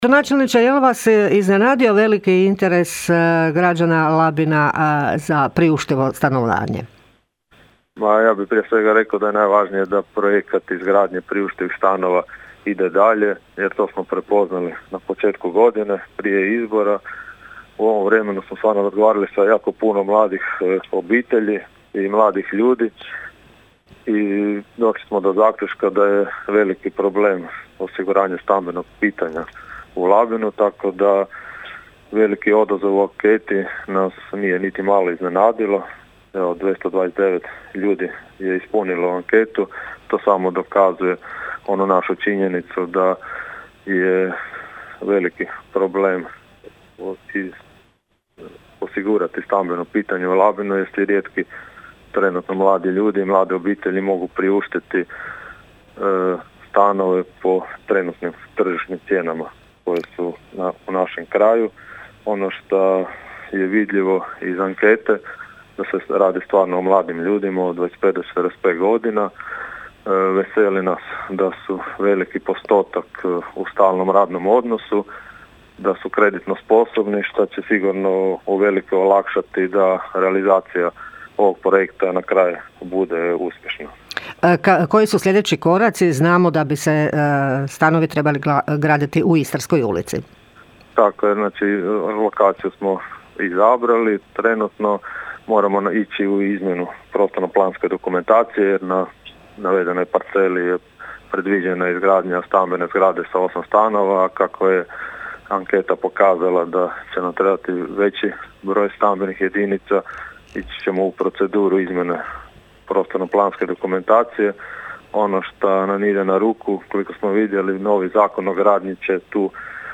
O rezultatima i njihovom značenju razgovaramo s gradonačelnikom Labina Donaldom Blaškovićem: (
ton – Donald Blašković).